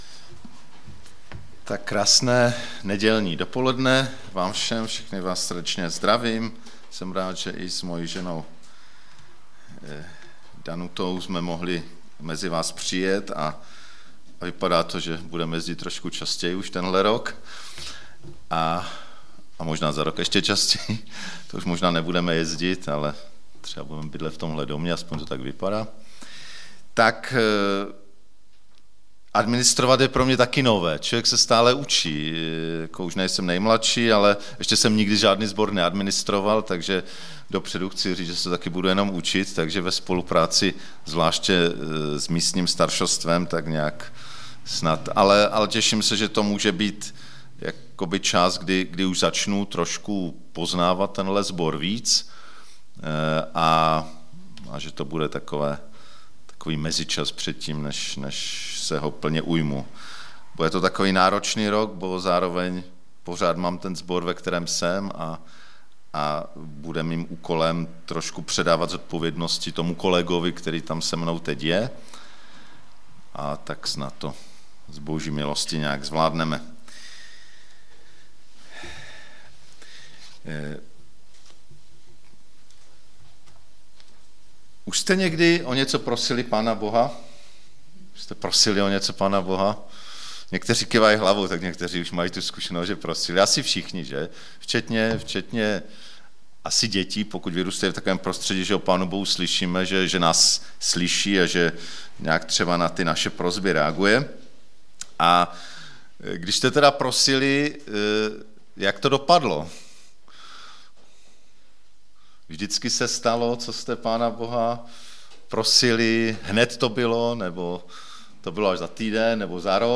Hlavní zaměření kázání ale bude na rozbor proseb tří osob (či jejich skupin) k Ježíši, co je k té prosbě motivuje, jestli je Ježíš vyslyšel a co to o Ježíši říká. Z rozboru vyplývá, že se vyplatí Ježíše prosit, pokud máme postoj víry, důvěry a ochoty naplnit vůli Boží spíše než svou.